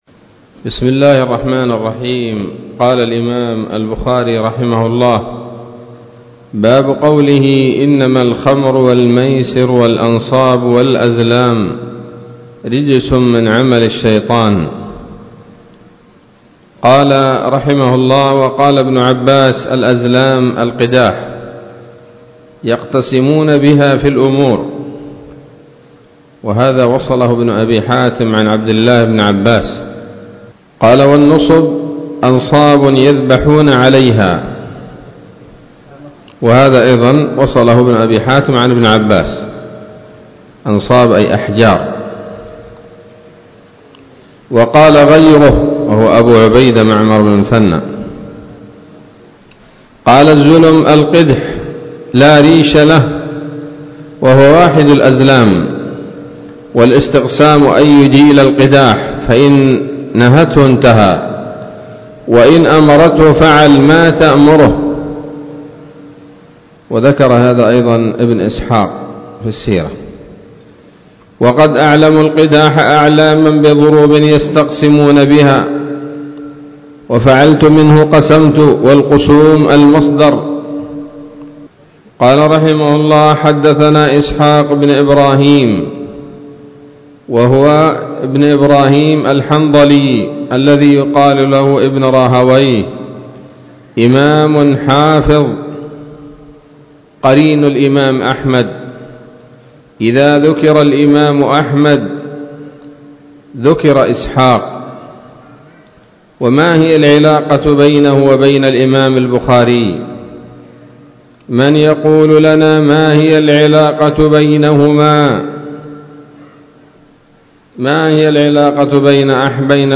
الدرس الثالث والتسعون من كتاب التفسير من صحيح الإمام البخاري